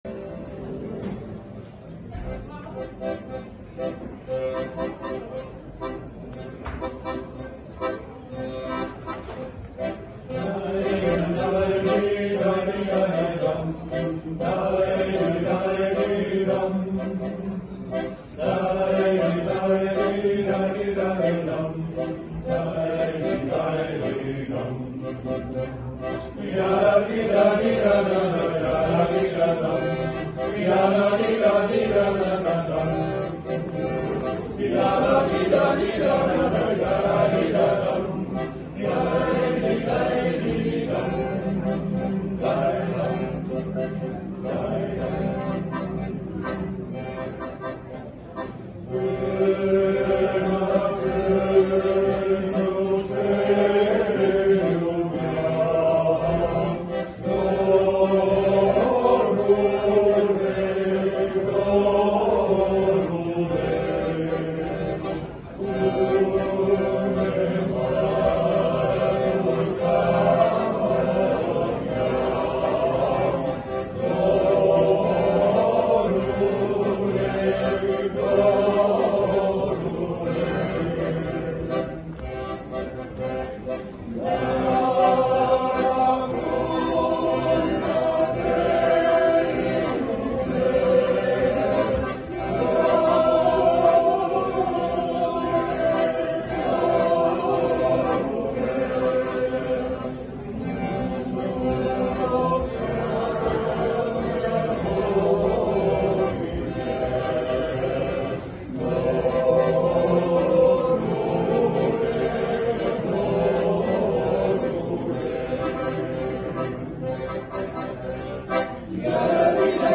13 mei 2023, Fulco’s Festival, IJsselstein, 3 optredens.